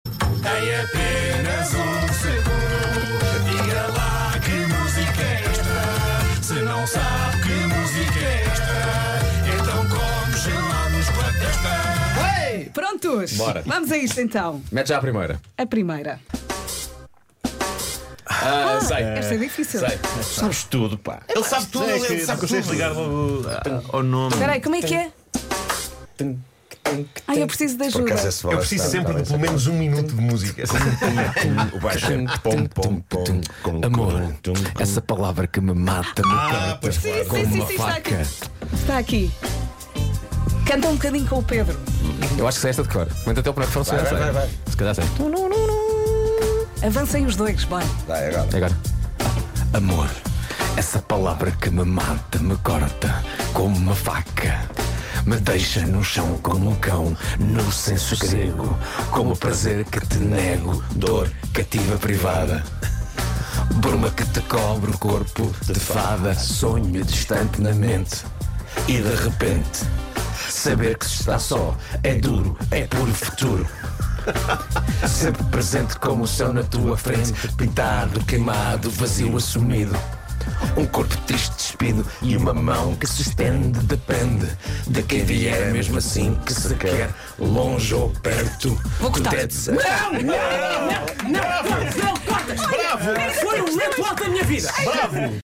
Inédito: Pedro Abrunhosa e Vasco Palmeirim cantam juntos!
Isto aconteceu durante um especial "Um Segundo de Música", dedicado ao Pedro Abrunhosa.